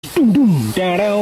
Silly Ring